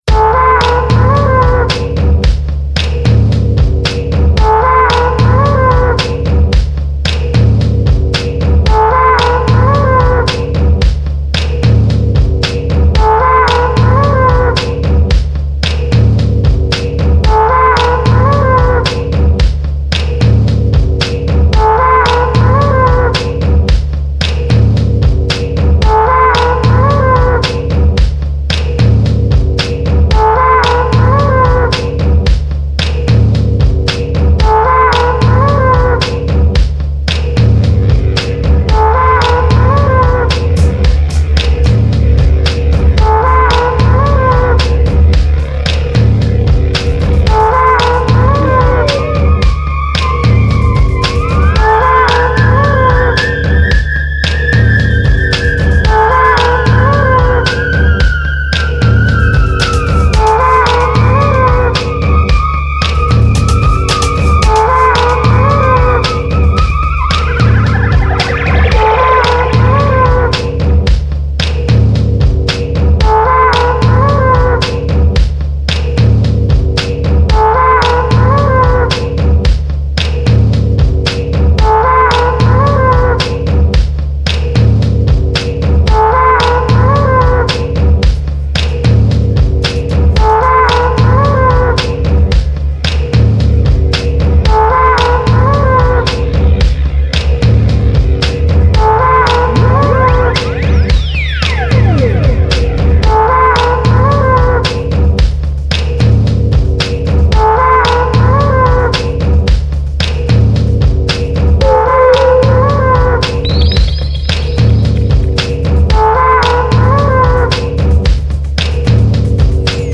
Genre: Acid-Wave.